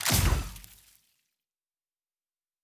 pgs/Assets/Audio/Sci-Fi Sounds/Weapons/Weapon 09 Shoot 2.wav at master
Weapon 09 Shoot 2.wav